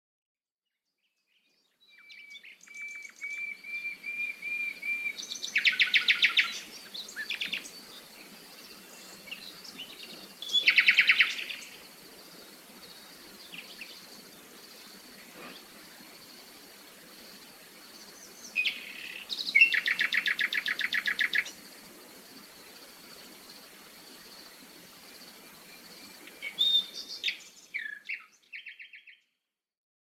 The rising sun and the peaceful harmonies of morning birds singing songs by a soothing nearby running brook. Bird songs, bees, flies, rooster, crickets, cicadas and dog join the chorus creating a blissful day in the countryside.
Sample-Countryside-05-The-Nightingale-and-the-Distant-Brook.mp3